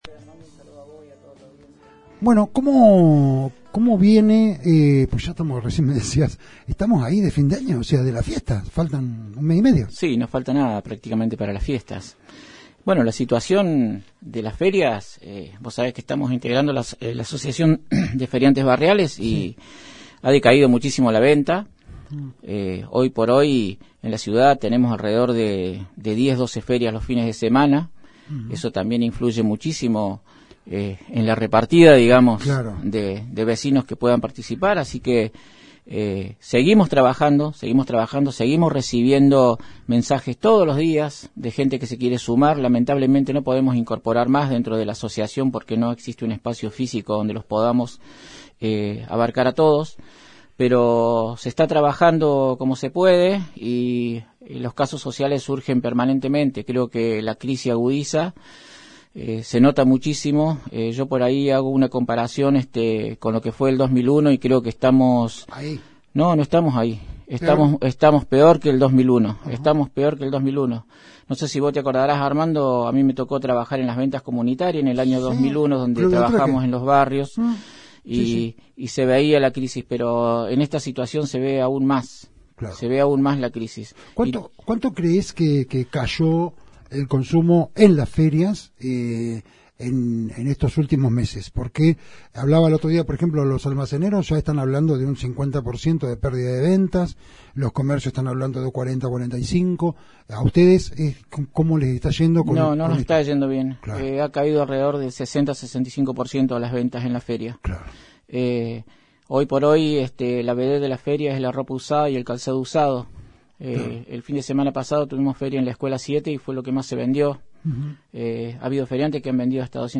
Fuente: Resumen Económico, Radio Provincia.